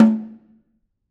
Snare2-HitNS_v6_rr1_Sum.wav